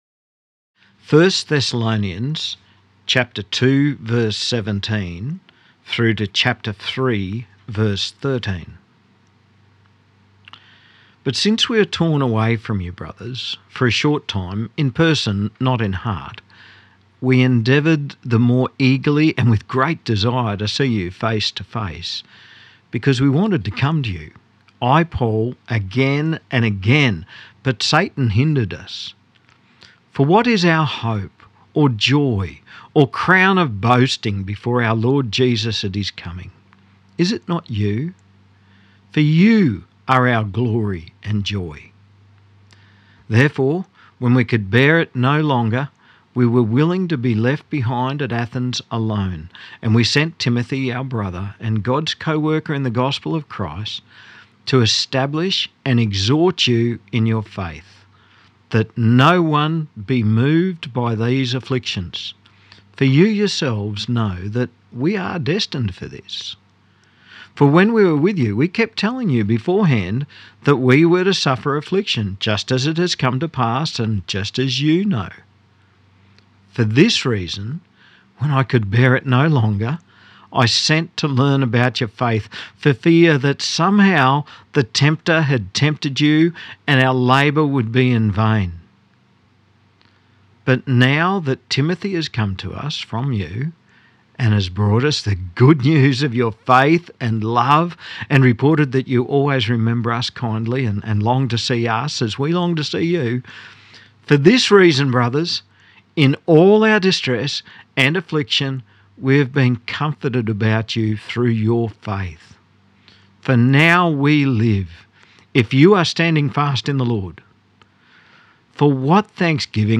Weekly messages of the Bush Disciples evangelical Christian Church in the St George QLD district.